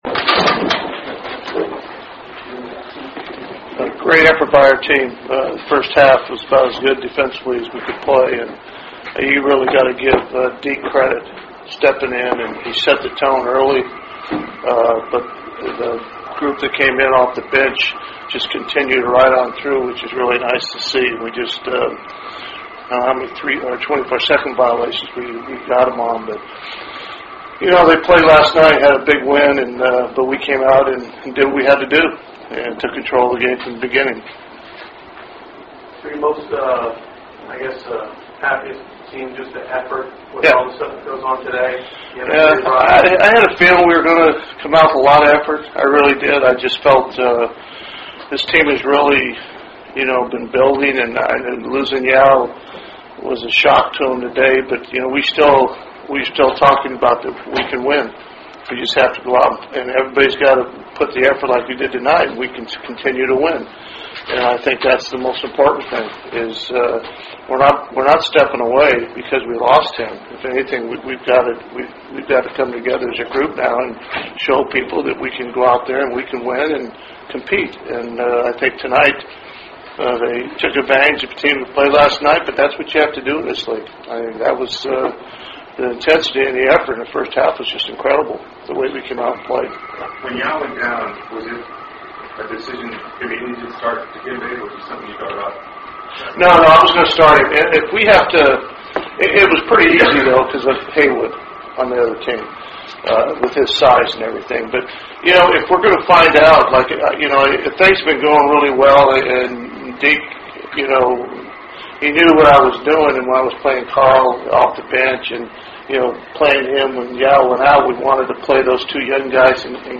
HOUSTON, TX (Toyota Center) -- Take a listen to Rockets head coach Rick Adelman's post-game press conference after the Rockets routed the Washington Wizards 94 to 69 Tuesday night: